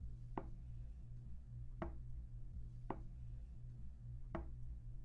滴答作响的厨房挂钟
描述：用Roland R05录制的Ticking Kitchen Clock
Tag: 时钟 滴答 蜱滴答 挂钟